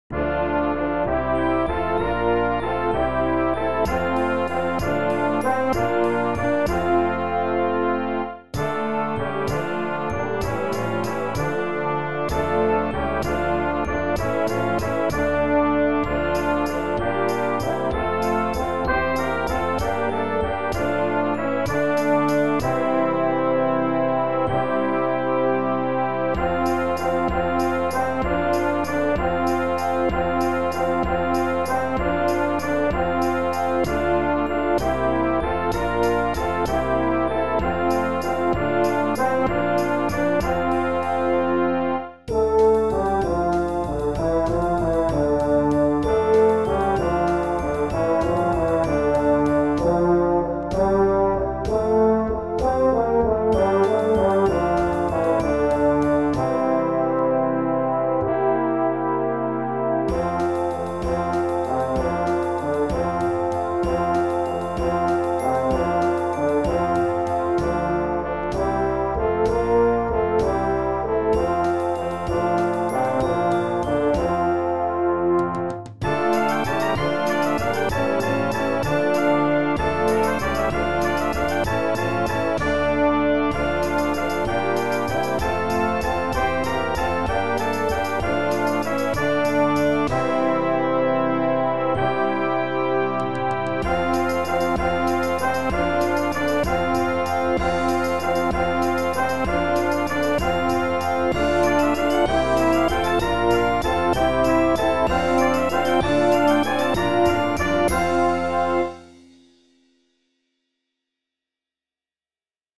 88B1 Brass Band $20.00 **
(computer generated sound sample)
1st verse  - Full band with solo Cornet on Melody.
Last verse - full band but with a stronger ending